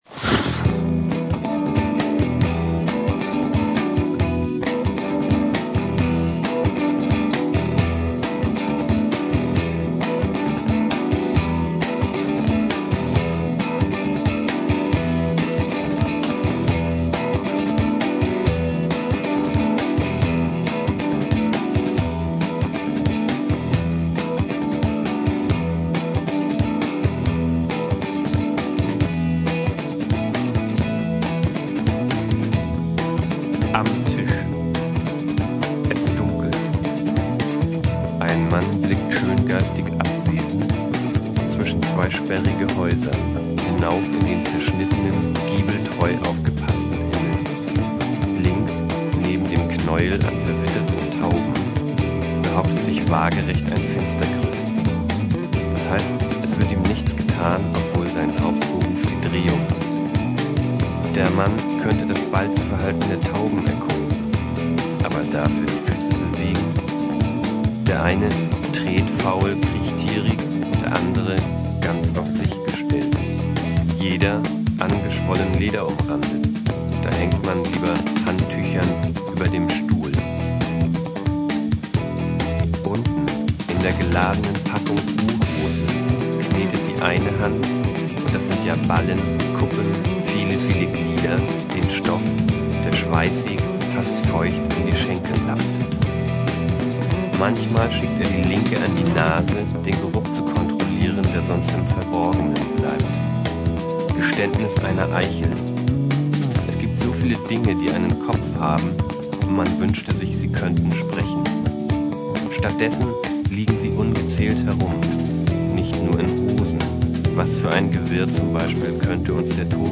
Drums
Bass, Noises
Guitars, Voice, Words
Rubrik: Hörspiel  Indie